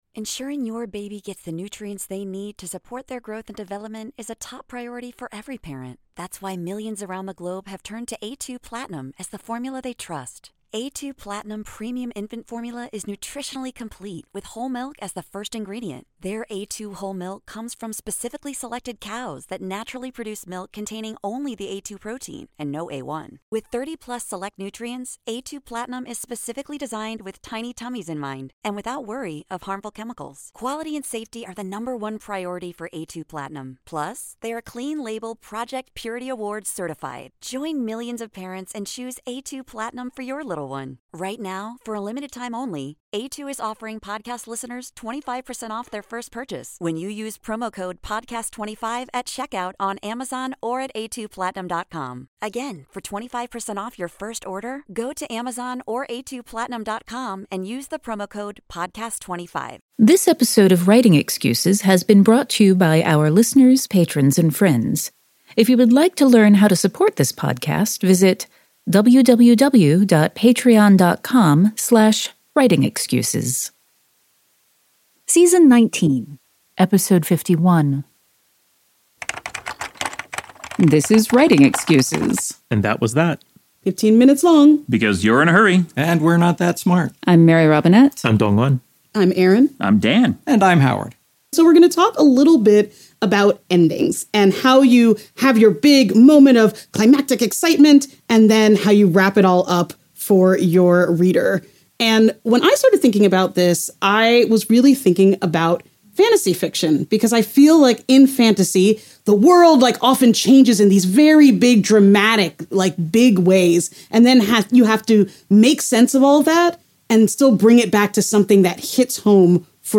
This episode was recorded live at our 2024 Writing Excuses Cruise.